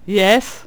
khanat-sounds-sources/_stock/sound_library/voices/voice_selections/cudgel_select1.wav at main